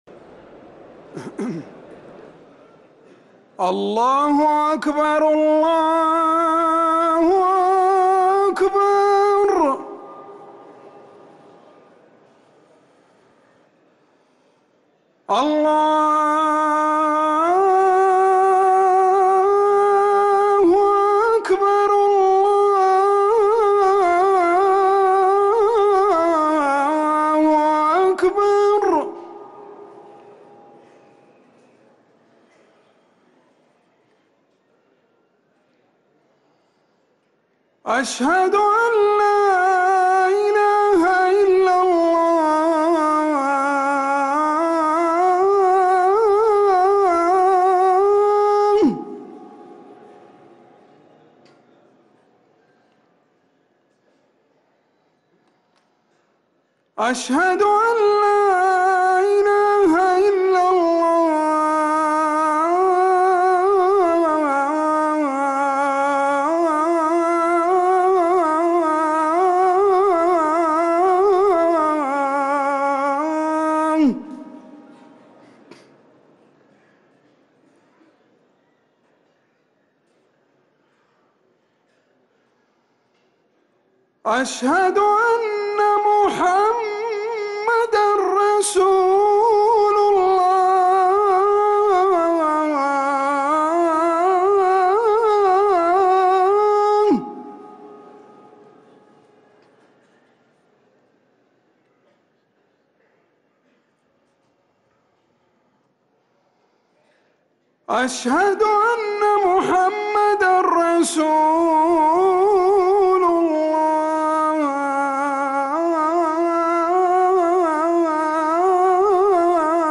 أذان العشاء للمؤذن عمر سنبل الاثنين 21 ربيع الأول 1444هـ > ١٤٤٤ 🕌 > ركن الأذان 🕌 > المزيد - تلاوات الحرمين